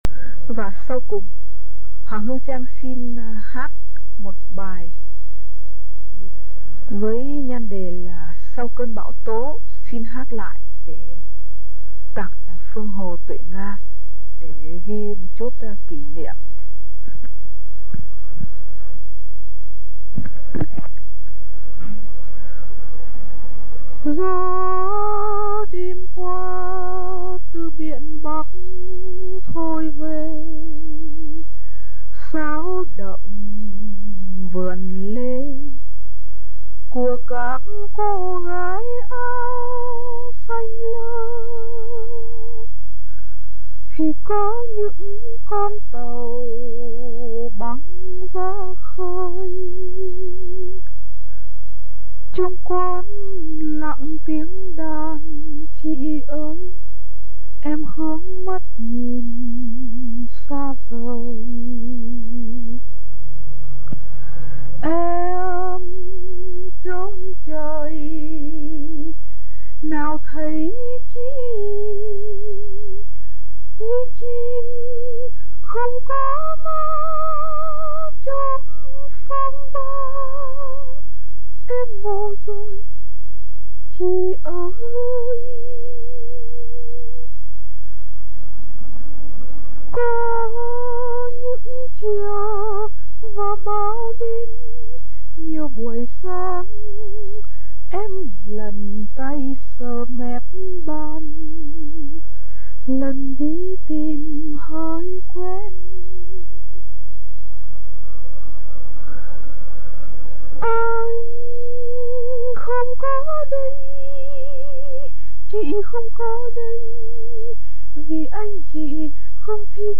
tiếng hát tha thiết, tràn đầy cảm khái, pha chút năo nuột
Những bài trên đây là "xuất khẩu thành ngâm/ xuất khẩu thành ca" , không có nhạc đệm.